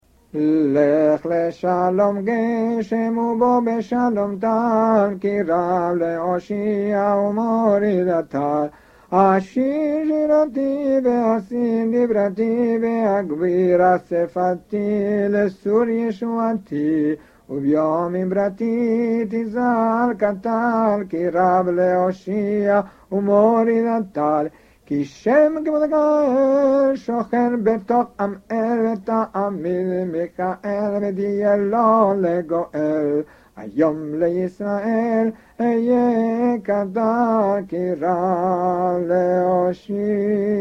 On the first day of Pesah, we officially stop praying for rain and instead begin praying for dew. A special prayer for dew is therefore sung during the holiday Musaf.